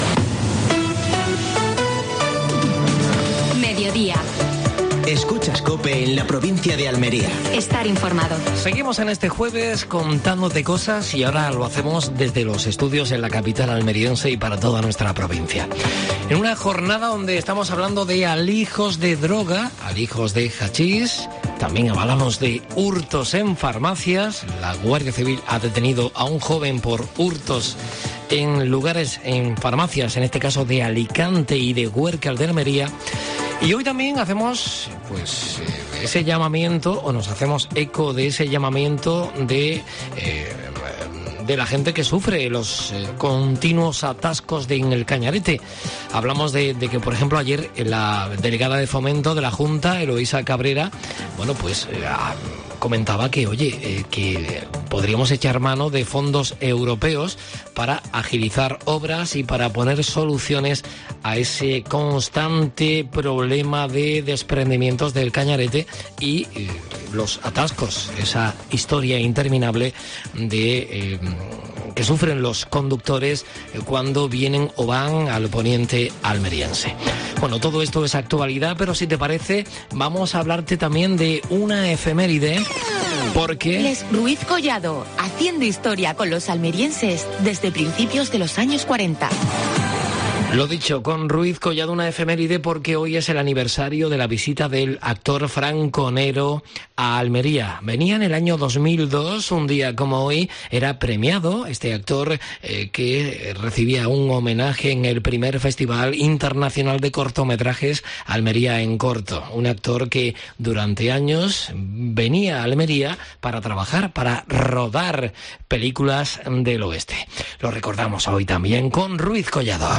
AUDIO: Actualidad en Almería. Entrevista a Ismael Torres (alcalde de Huércal de Almería).